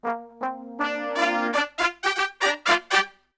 FUNK2 GM.wav